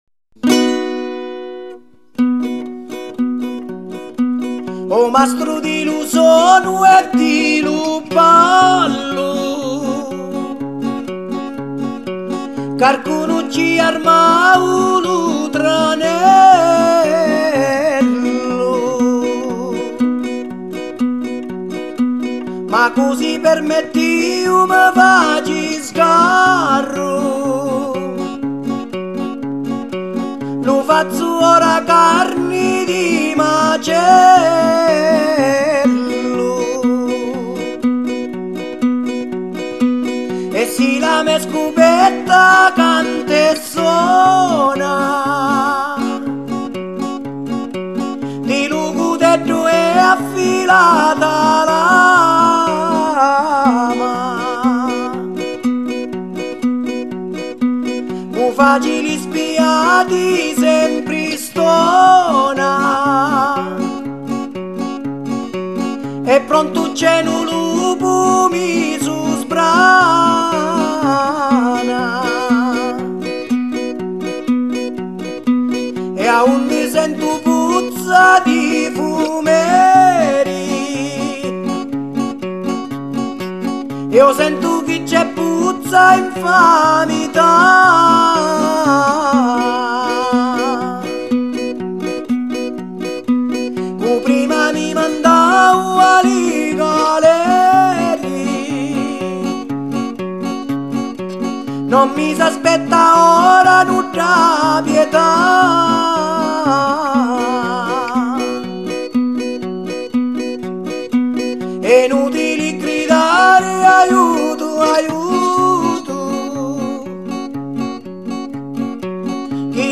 Жанр: Mafia Folk